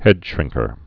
(hĕdshrĭngkər)